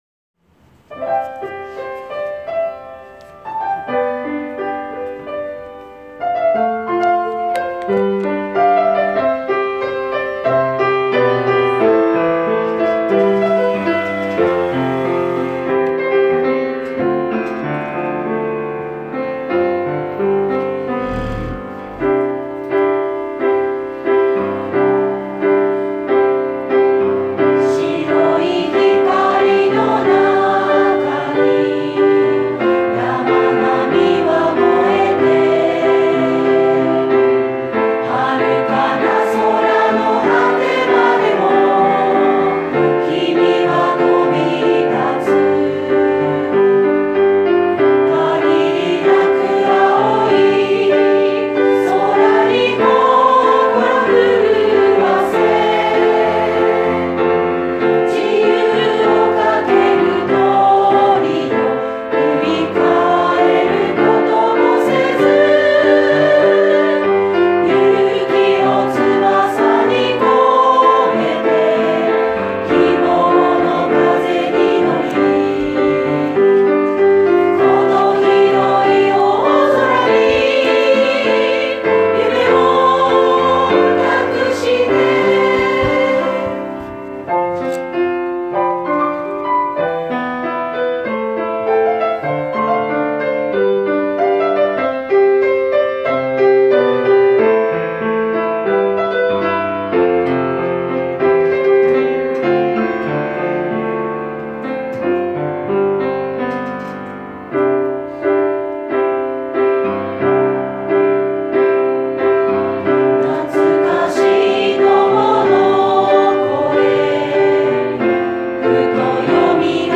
今年度の卒業式では、参列者全員で「旅立ちの日に」を合唱します。 例年とは違う卒業式を少しでも感動あるものにしようと、教職員も時間を見つけ、向け練習に励んでいます。
練習での歌声をアップしましたので、下記をクリック下さい。 ♪ 合唱「旅立ちの日に」